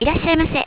下載歡迎光臨日語音效
IRASHAI.wav